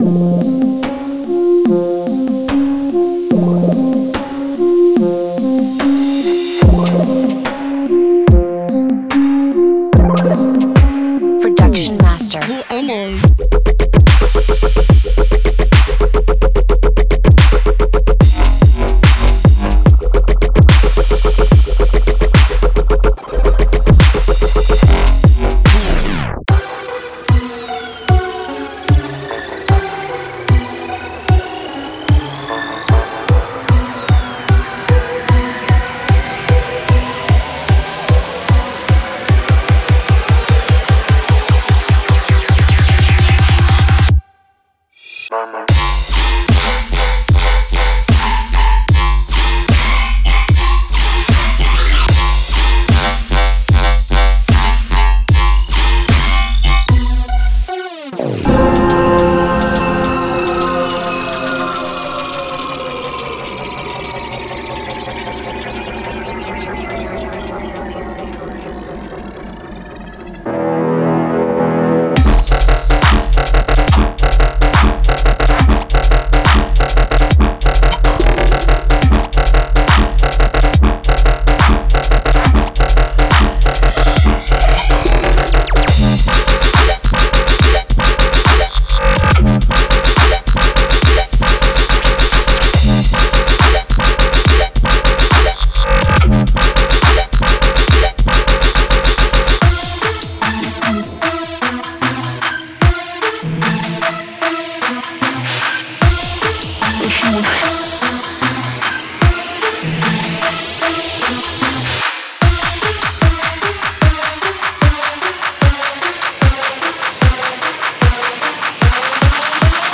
超重低音-准备使用锥形锥形中音尖叫器和巨大的次级咆哮者,其调制的纹理会穿透工作室的墙壁。“
在里面，您会发现大量的颠簸声,坚硬的网罗，脆皮陀螺，工业打击乐器等等。